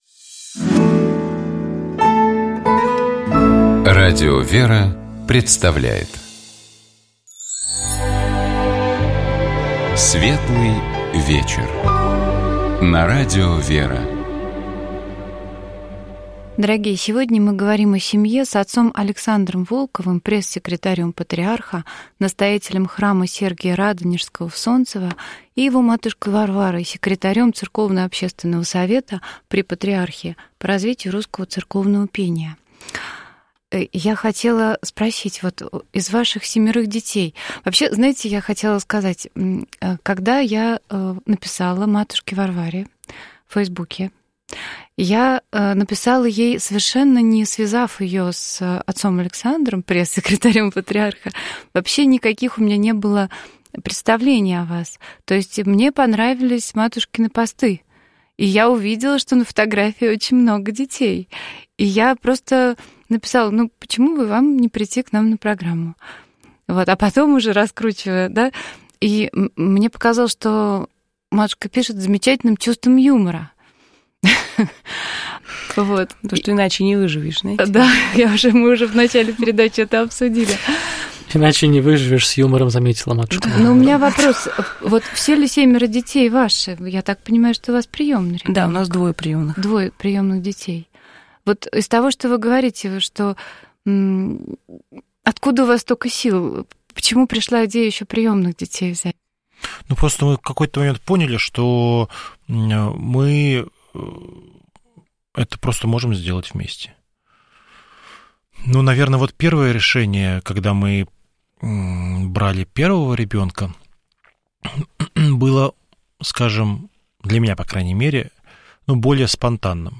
Мы говорили с нашими гостями об их опыте построения семейного счастья, о воспитании детей, родных и приемных, и о том, как объединяются церковное служение и семейная жизнь.